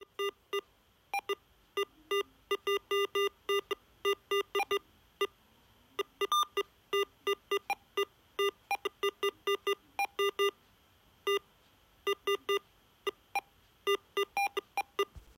и пиликает что с датчиком , что без него